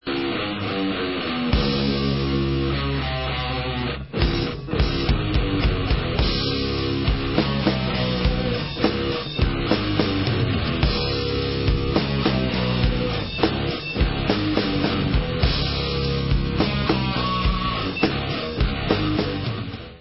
Rock/Alternative Metal